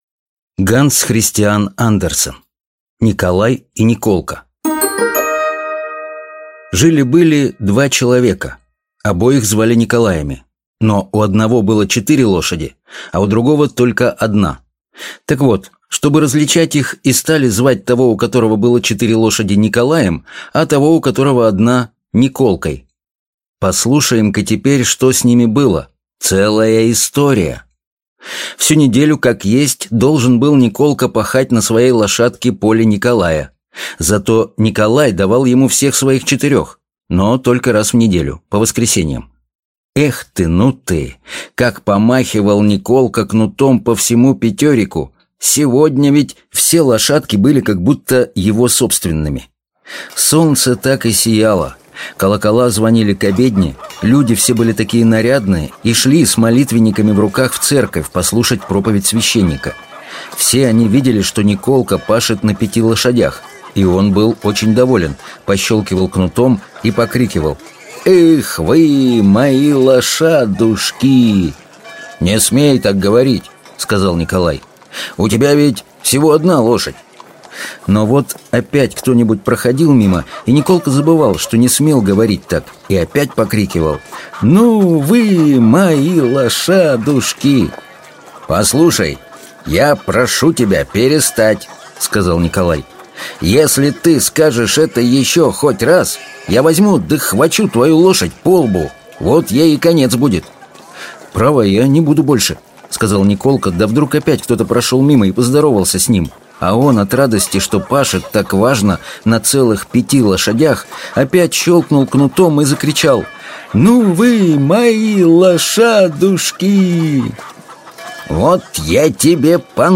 Аудиокнига Николай и Николка | Библиотека аудиокниг